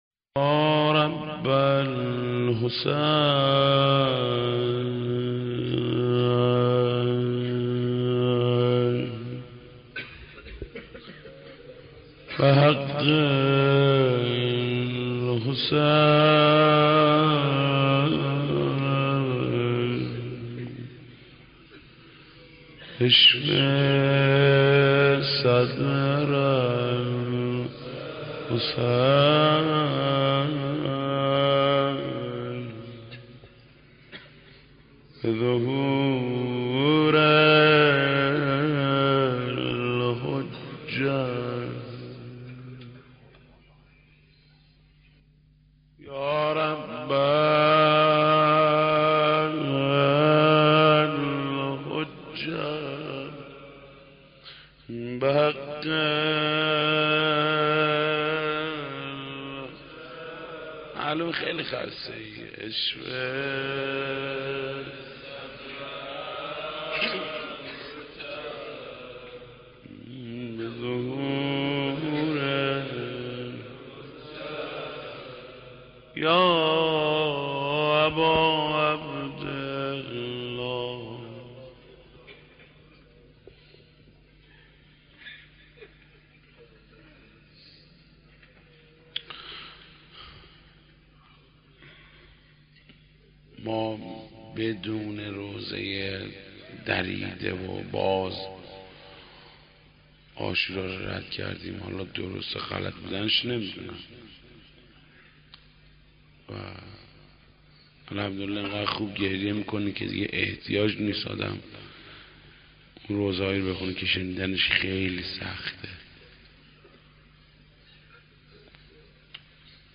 مداح : محمود کریمی